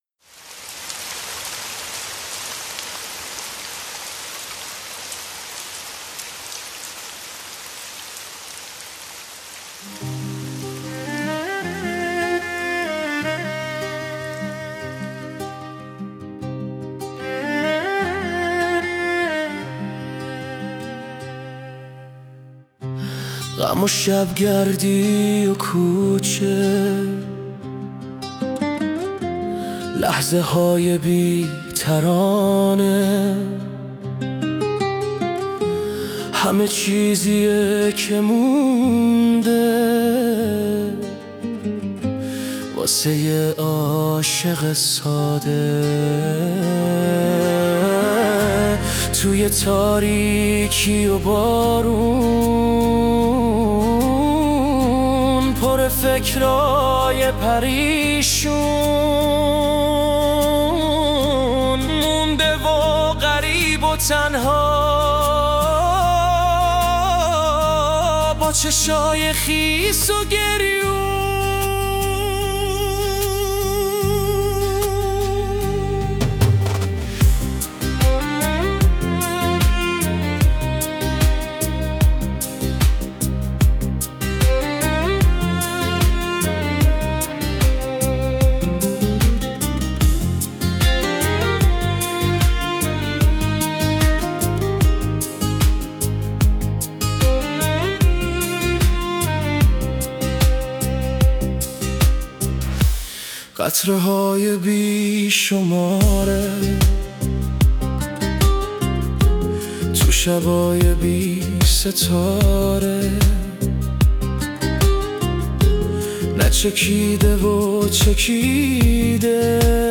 خواننده هوش مصنوعی